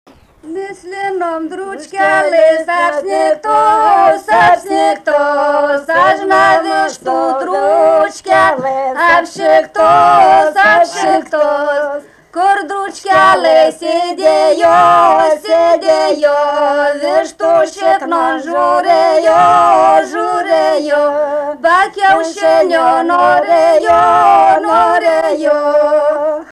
Dalykas, tema daina
Erdvinė aprėptis Kriokšlys
Atlikimo pubūdis vokalinis